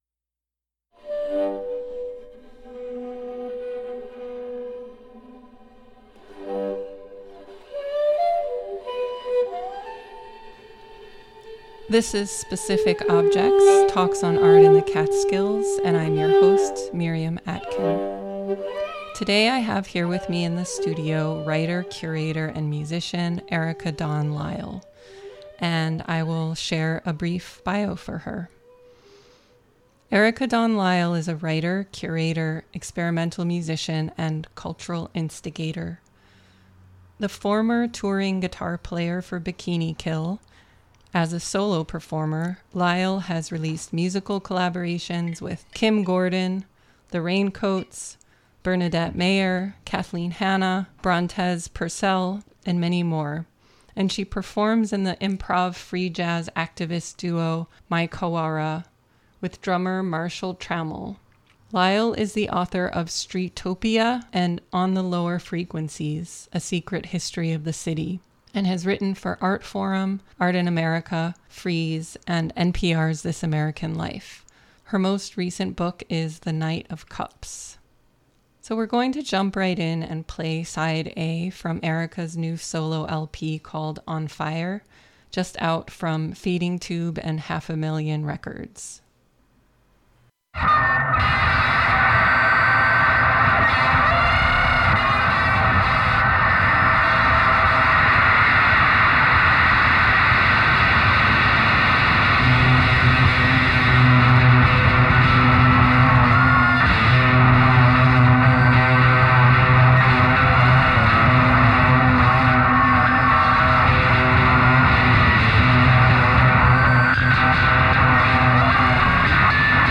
"Specific Objects" is a monthly freeform discussion